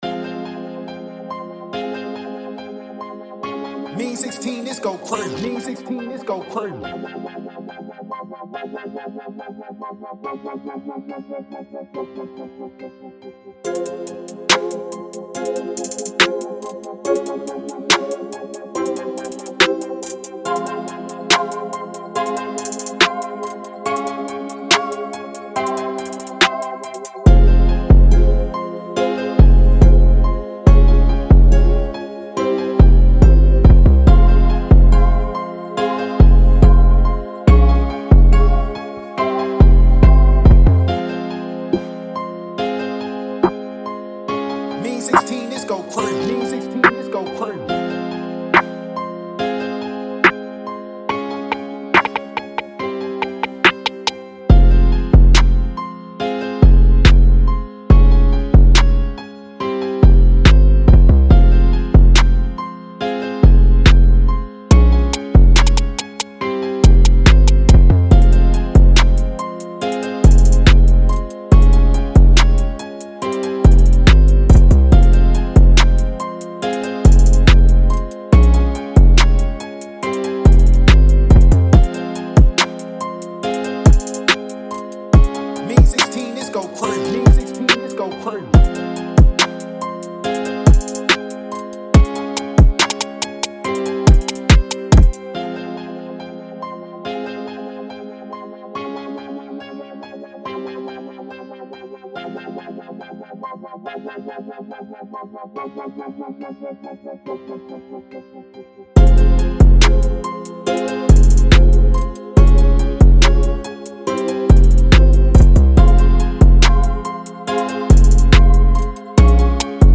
TYPE BEAT
F-Min 141-BPM